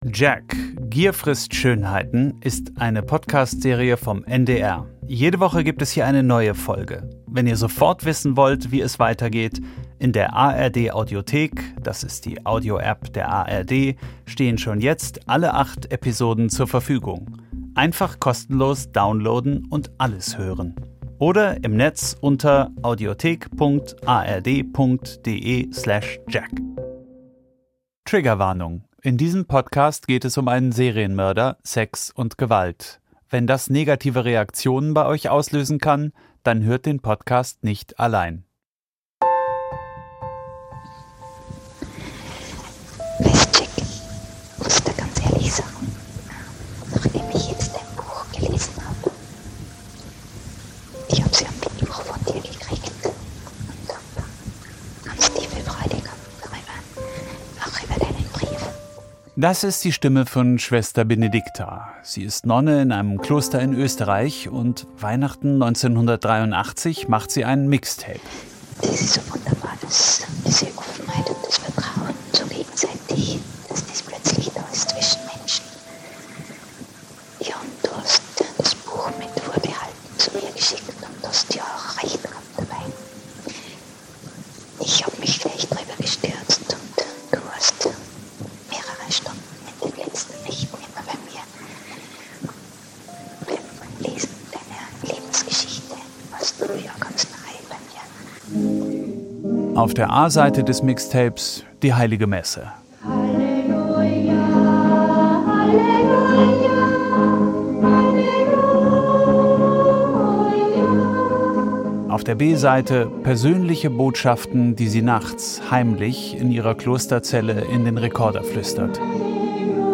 Radio- und Podcast-Serie